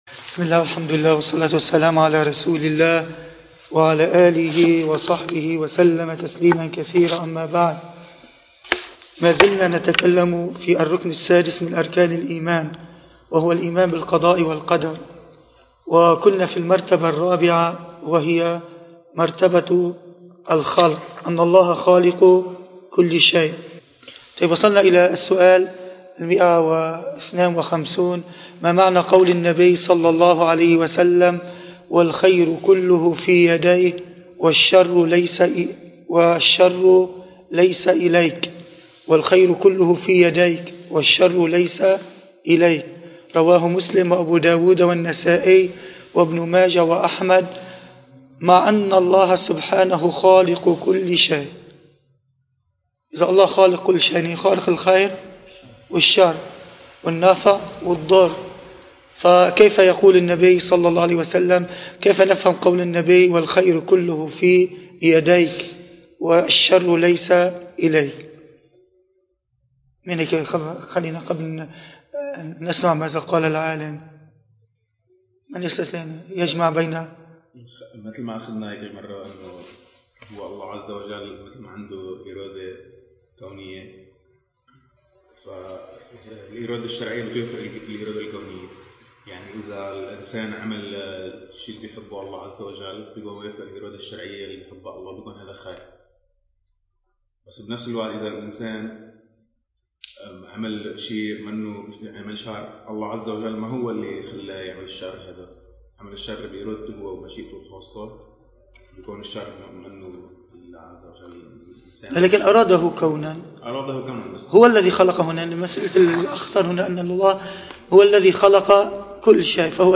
المكان: مسجد القلمون الغربي